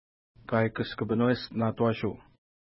Pronunciation: ka:jkəskəpi:nwest-na:twa:ʃu:
Pronunciation